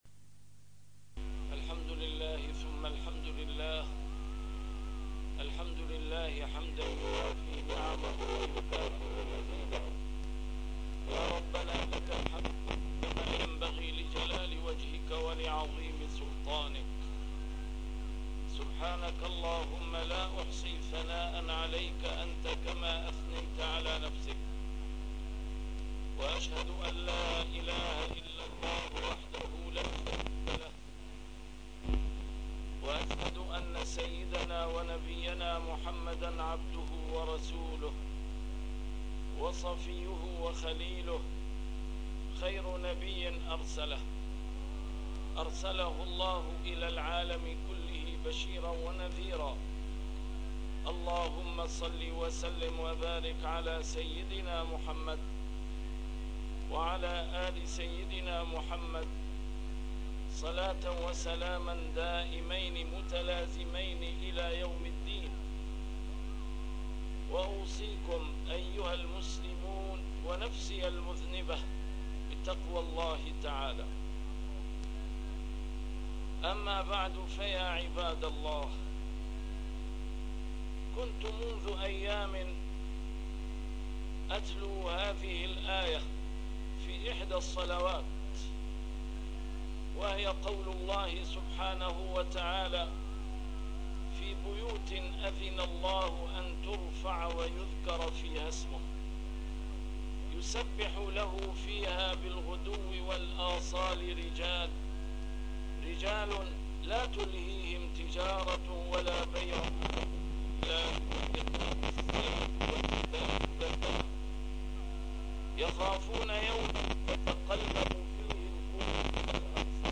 A MARTYR SCHOLAR: IMAM MUHAMMAD SAEED RAMADAN AL-BOUTI - الخطب - مصيبة اختفاء طلاب العلم الليليين من أسواق دمشق